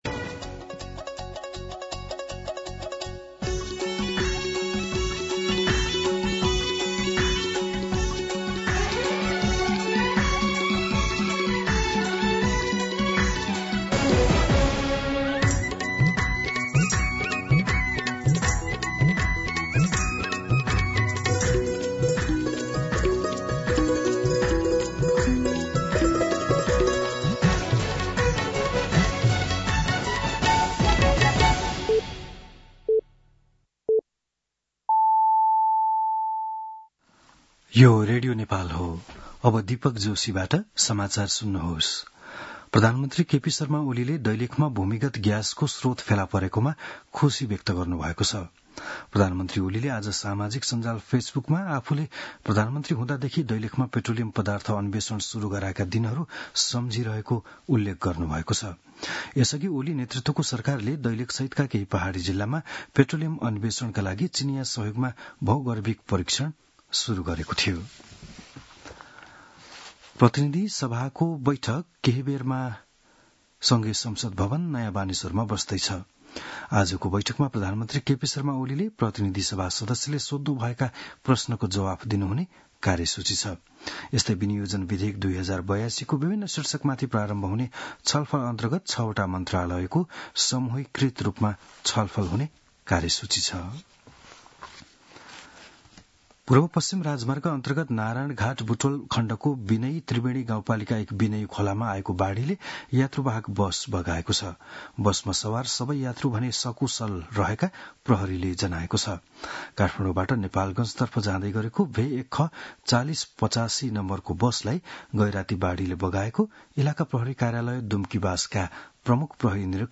बिहान ११ बजेको नेपाली समाचार : ६ असार , २०८२